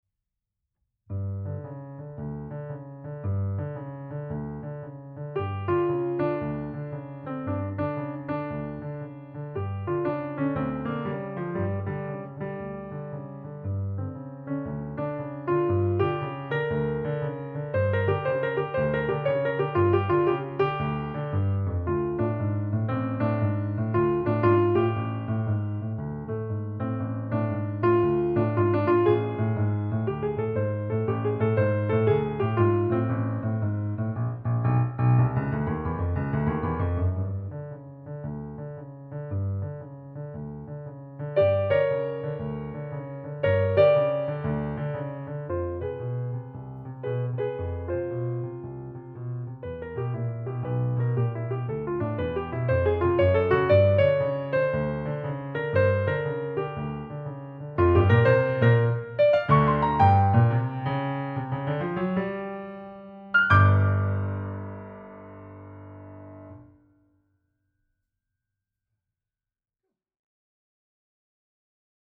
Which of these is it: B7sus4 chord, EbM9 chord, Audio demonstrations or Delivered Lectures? Audio demonstrations